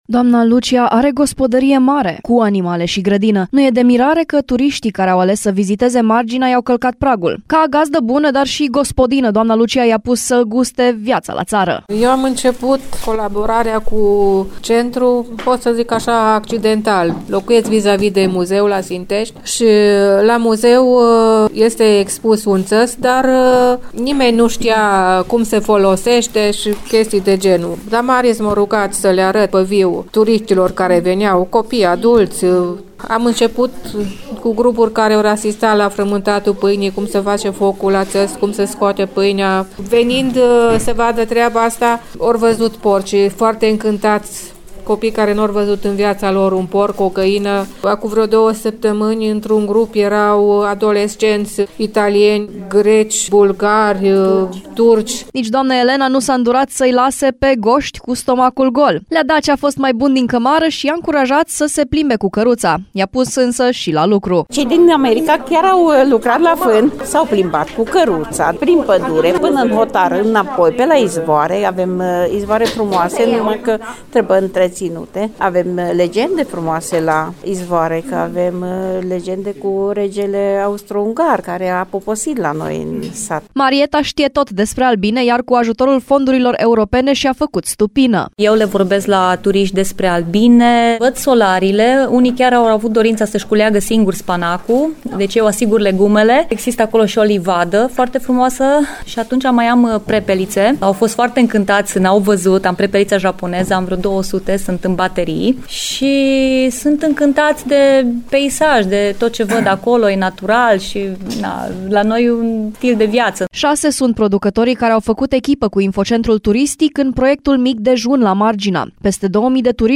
reportajul